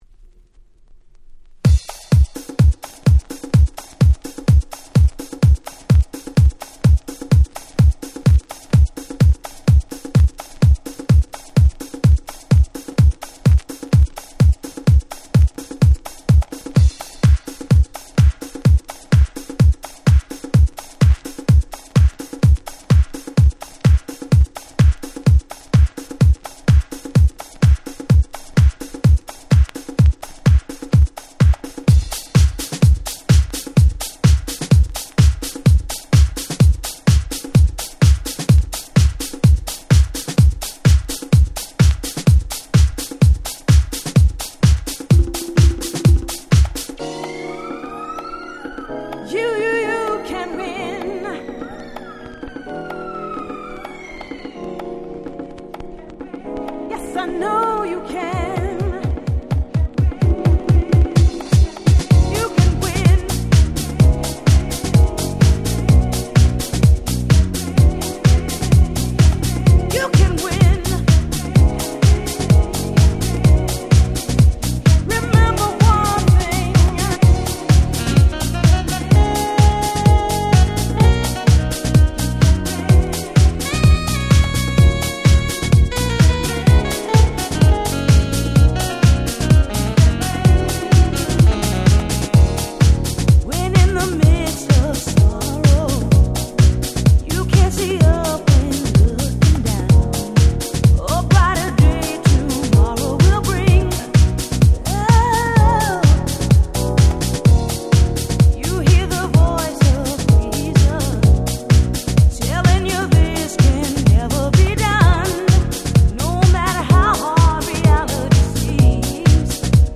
03’ Super Nice Cover Vocal House !!